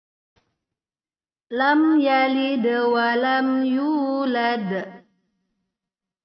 Qalqalah Sedang yaitu apabila salah satu dari huruf qalqalah sukun berada di akhir kata, cara membacanya yaitu langsung dipantulklan tanpa ditahan.